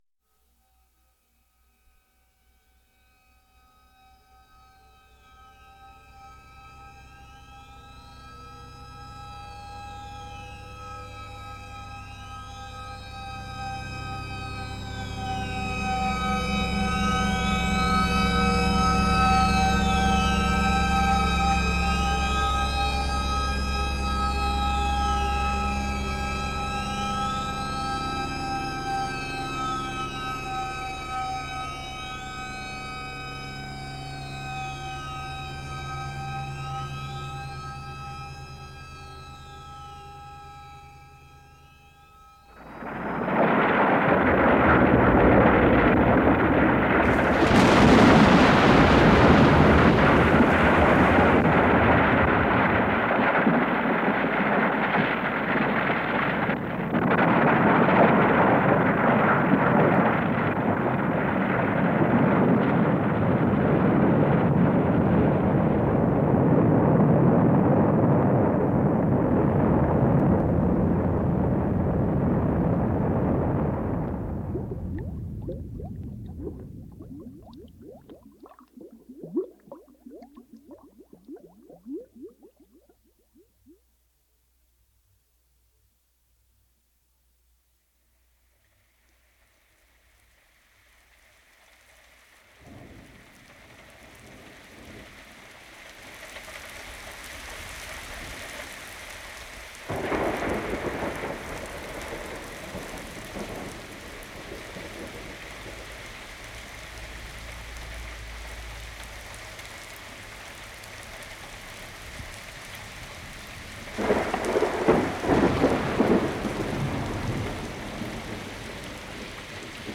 archivosonoro-the-sounds-of-earth.mp3